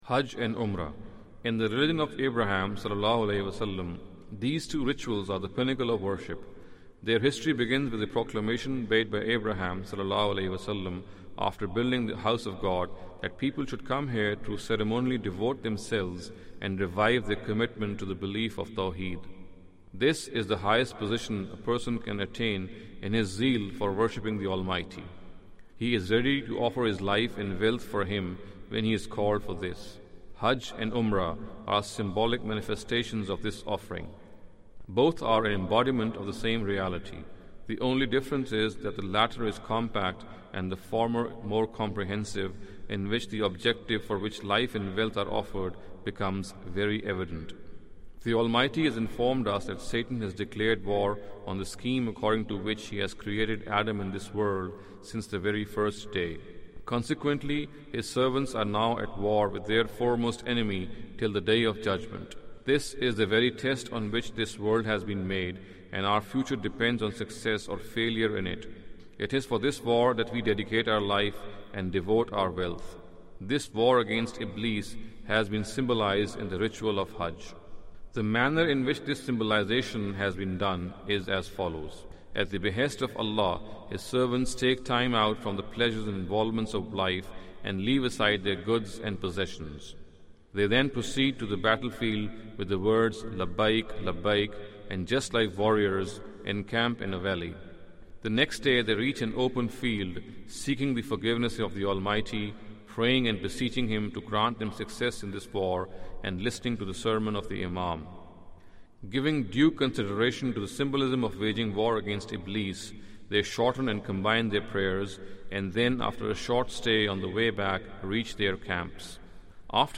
Audio book of English translation of Javed Ahmad Ghamidi's book "Islam a Concise Intro".